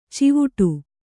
♪ civuṭu